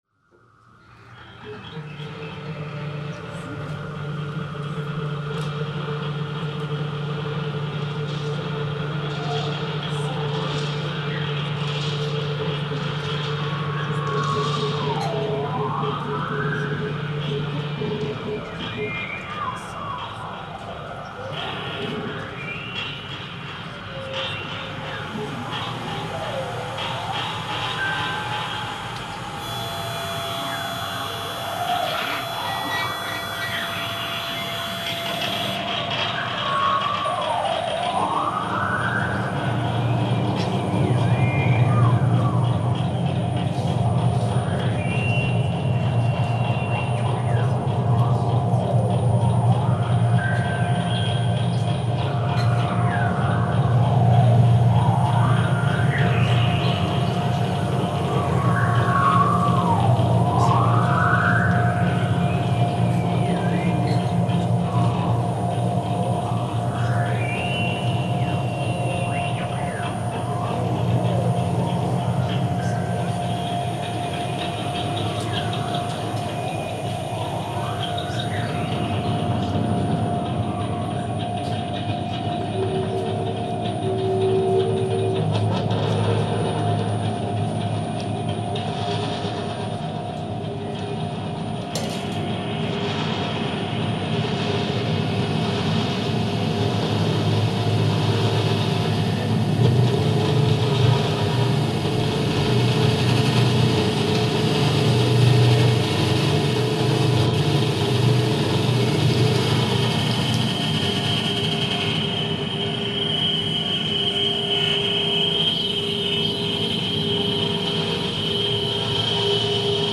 Un signal fm est diffusé dans la salle. Chaque opérateur (5 max) capte le signal radio d’un collage assemblé en amont de radios mondiales.
L’opérateur transforme ce signal par ajout d’effets et le rediffuse sur une deuxième radio. Les radios sont réparties sur scène comme un orchestre.